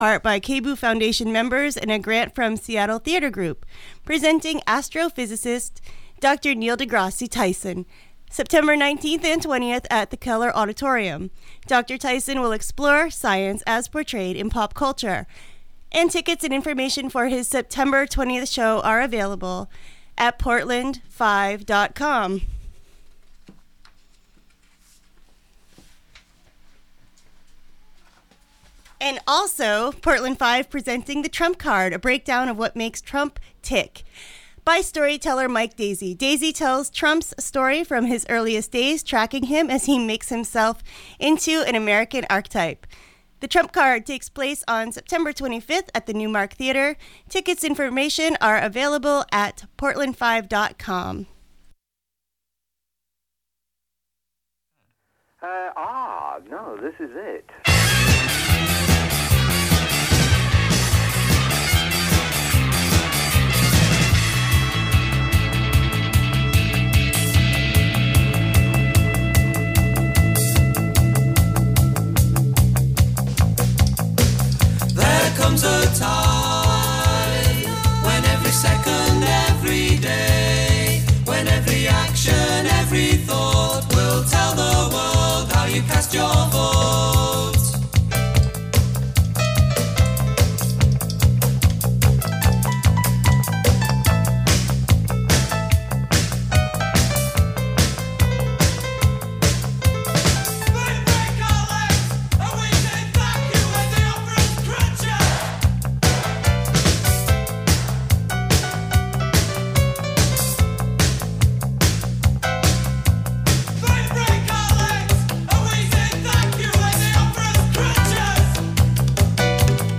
The conversation is always relevant and meaningful, often poems are involved, and always stories that can help you make sense of thi